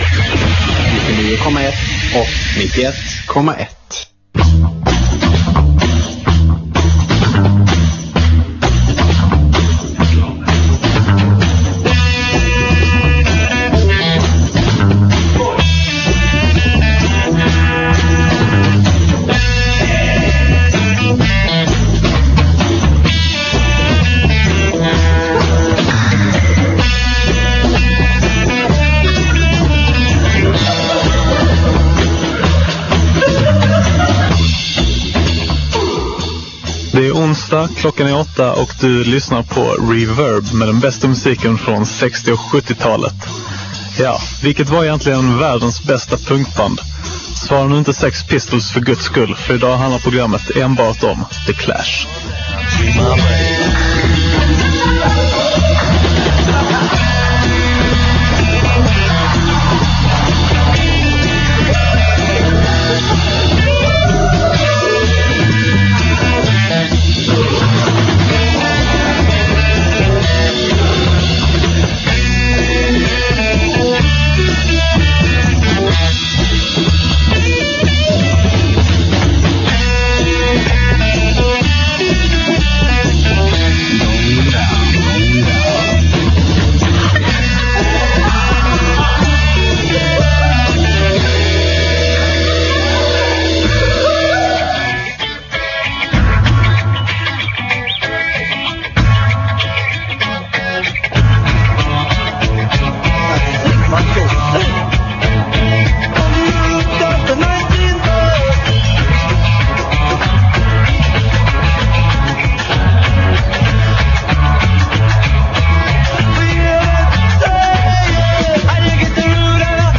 De flesta ser dock ett band som förenade hjärta med hjärna, bångstyrig revolt med genuint politiskt engagemang och råa rockriff med karibiskt gung. Säga vad man vill om dem, under en timme i dagens program är de definitivt det enda bandet som betyder något.